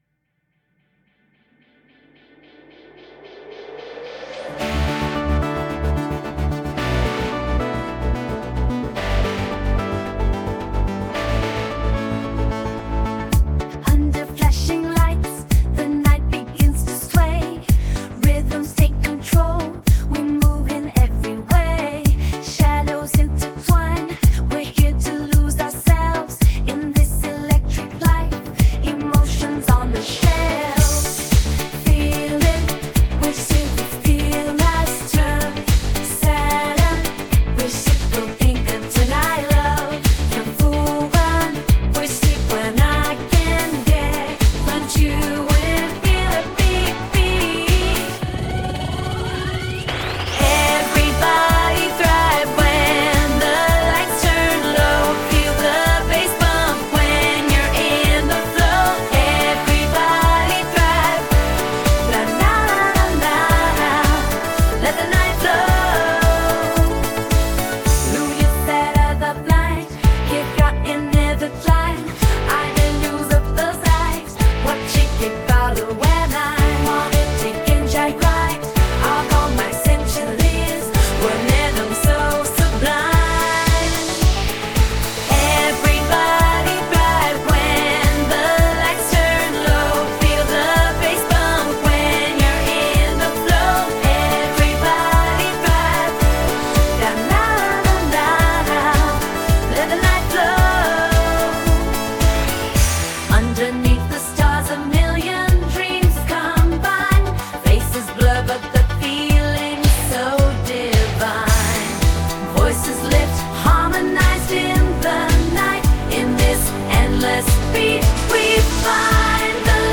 Доделываем на студии [pre-release], поп